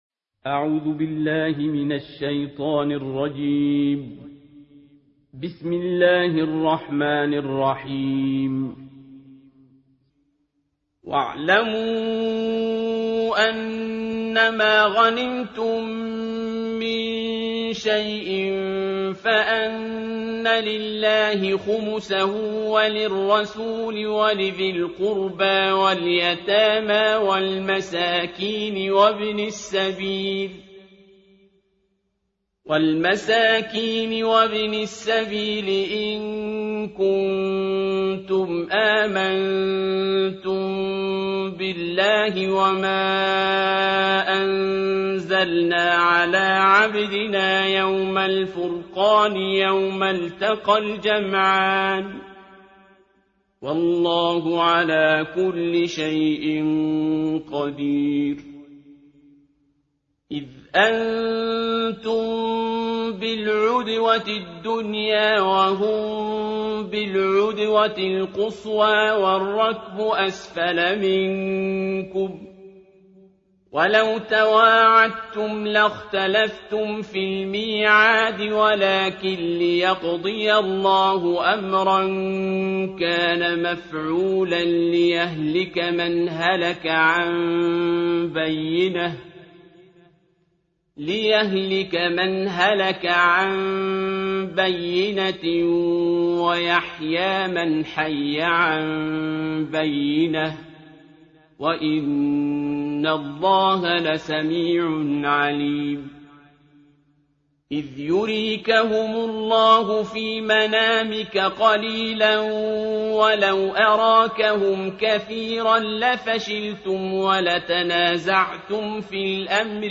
صوت تلاوت ترتیل جزء دهم قرآن‌کریم